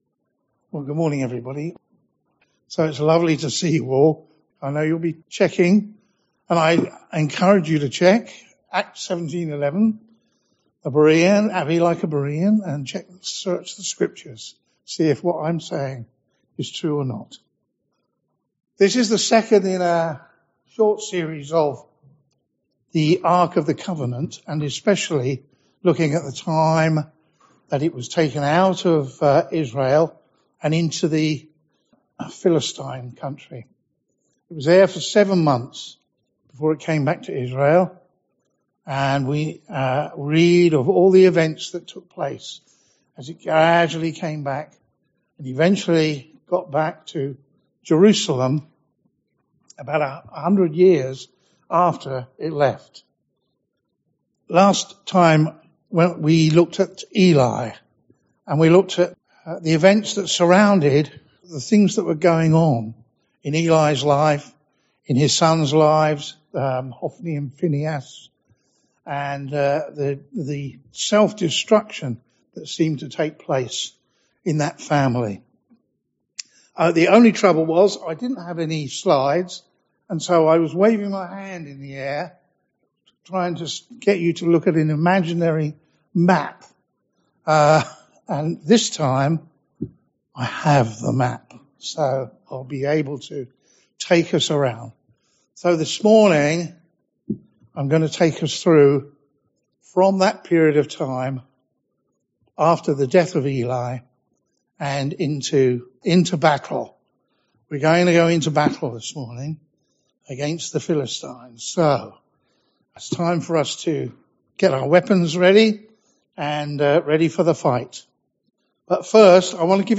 Series: Guest Speakers , Sunday morning studies , Topical Studies Tagged with topical studies